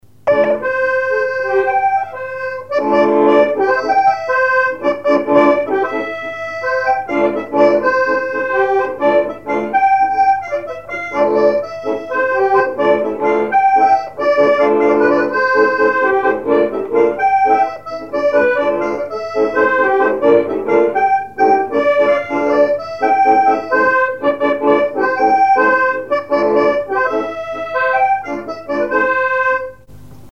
danse : polka des bébés ou badoise
instrumentaux à l'accordéon diatonique
Pièce musicale inédite